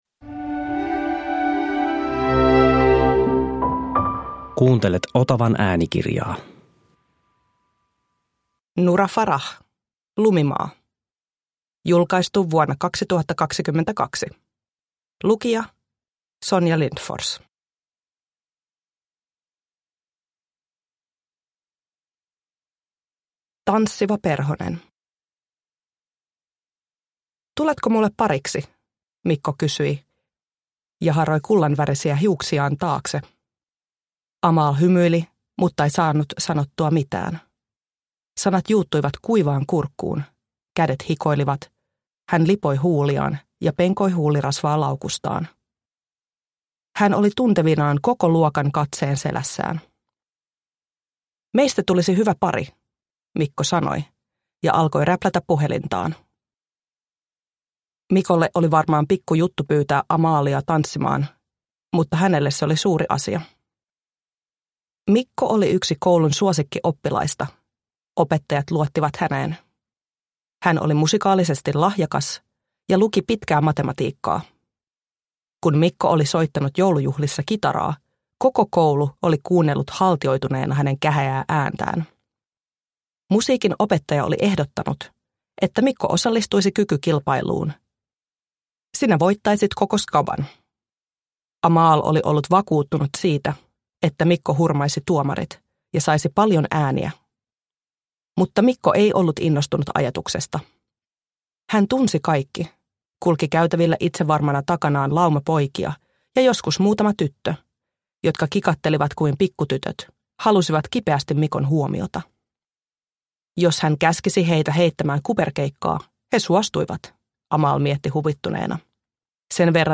Lumimaa – Ljudbok – Laddas ner